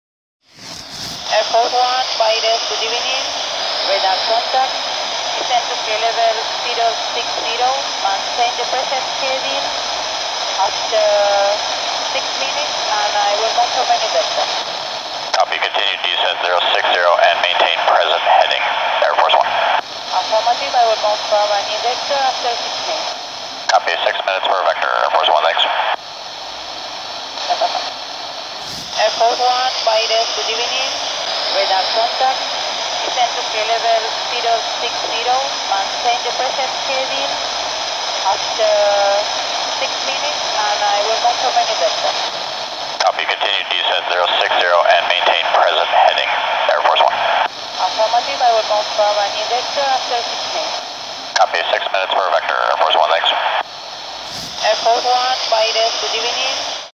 Compartimos el audio original entre el control de tránsito aéreo BAIRES Y EL AIR FORCE ONE ESCUCHALO.